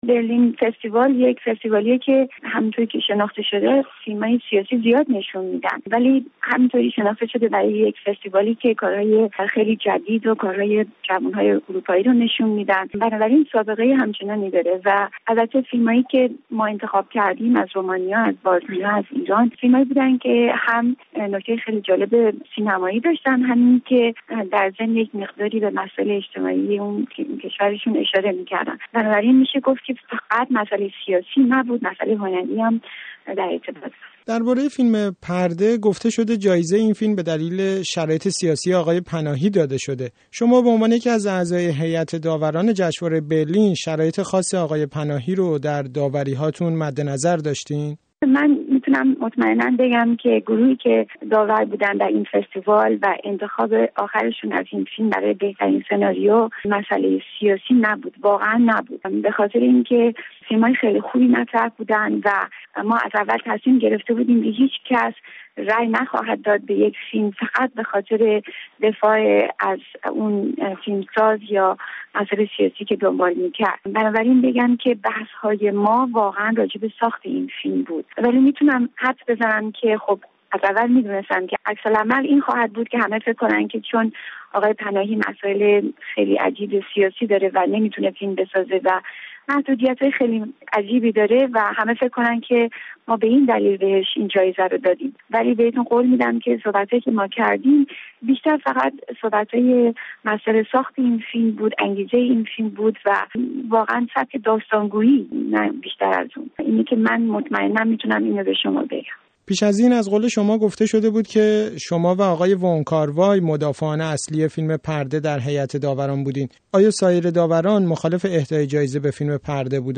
شیرین نشاط، هنرمند ایرانی و جزو هیات داوران جشنواره فیلم برلین در گفت وگو با رادیو فردا از فیلم پرده و جایزه جعفر پناهی می گوید.